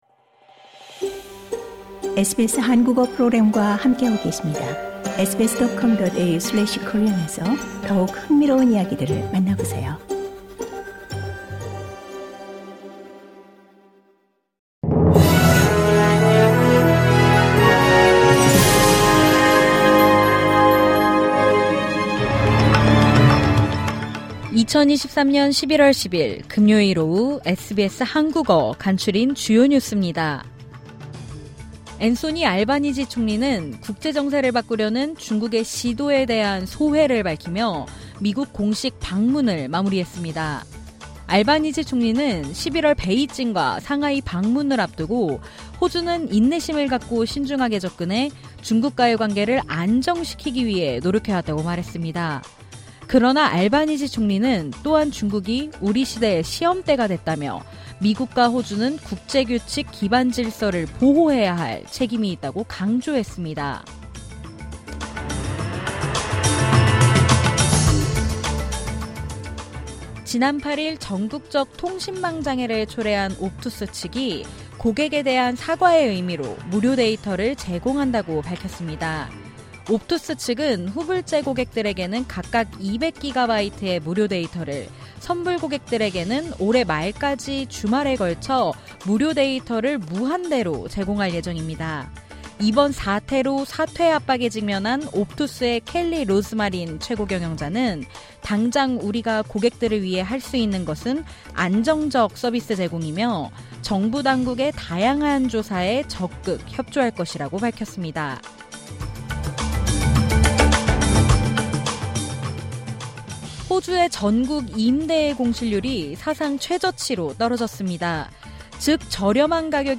2023년 11월10일 금요일 오후 SBS 한국어 간추린 주요 뉴스입니다.